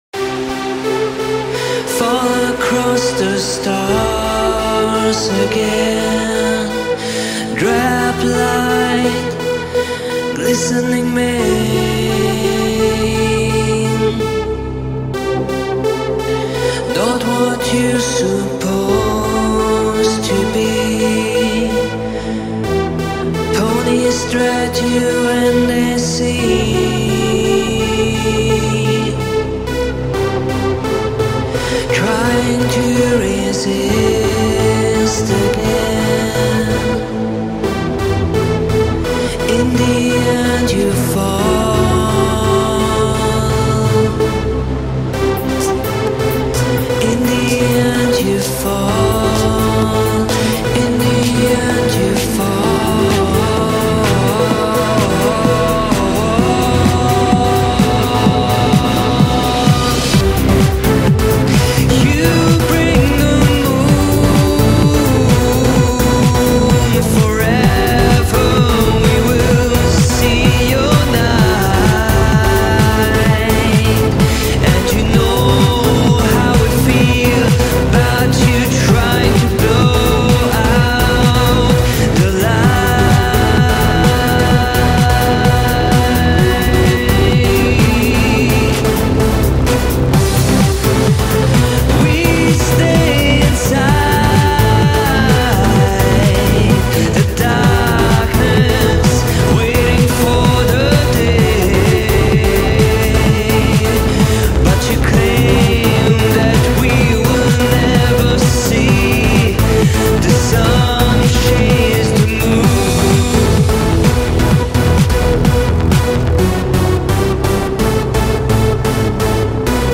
Sexy Vocals